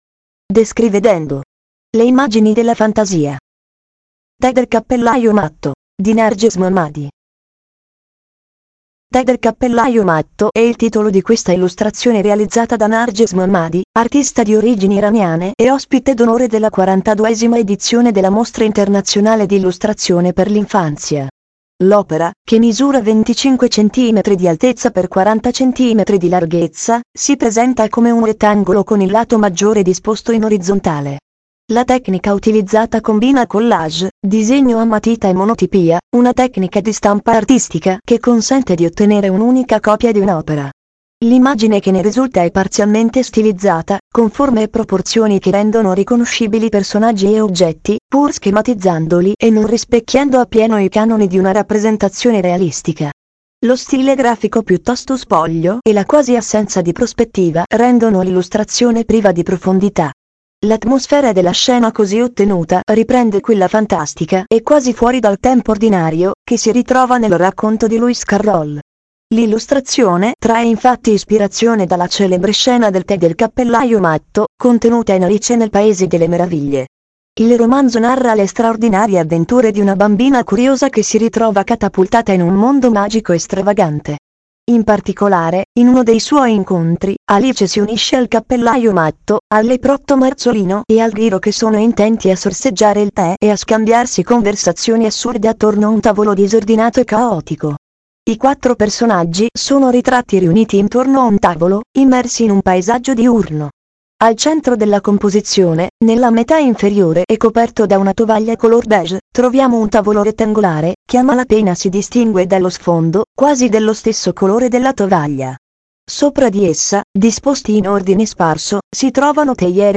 DescriVedendo ha realizzato la descrizione morfologica certificata dell’opera emblematica dell’esposizione, della quale si può fruire tramite un QR Code posto di fianco all’illustrazione.